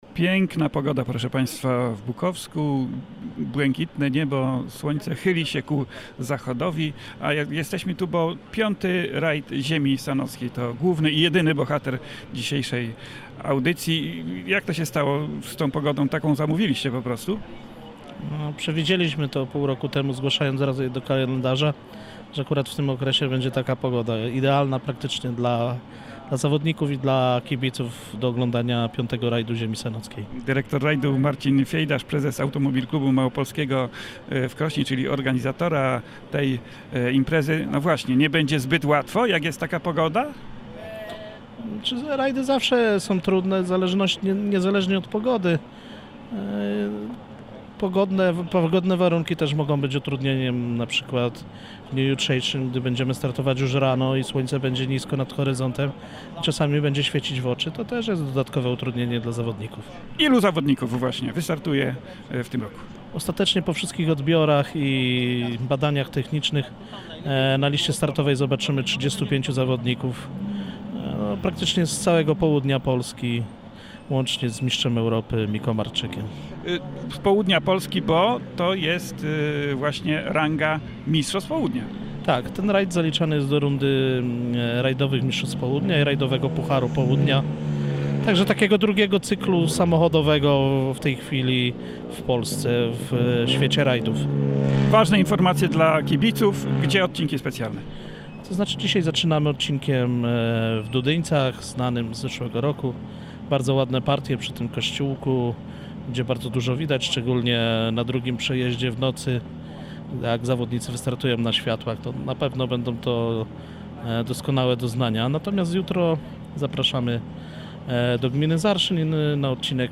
był na starcie imprezy w Bukowsku i rozmawiał z zawodnikami i organizatorami.